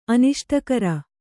♪ aniṣṭakara